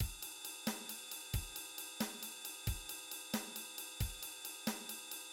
Drum beat
Simple duple drum pattern but with triplets: divides each of two beats into three.